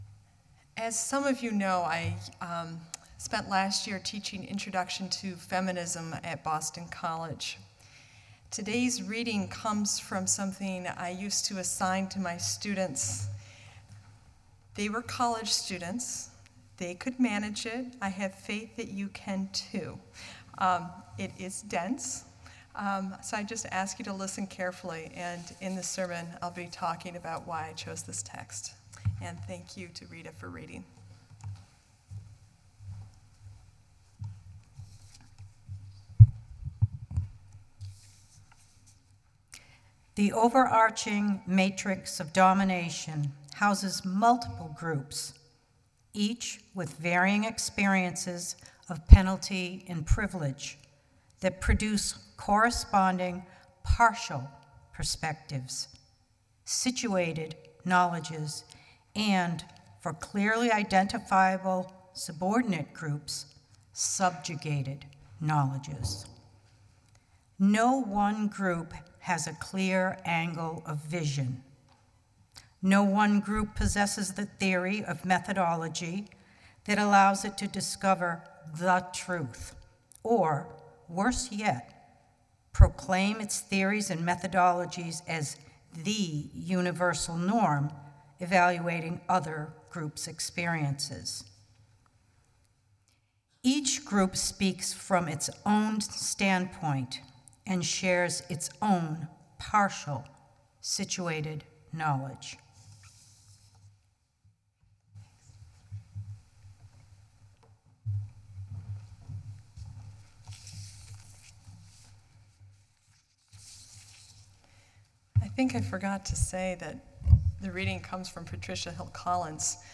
This is a recording of the complete worship service.
Sermon3_23_14.mp3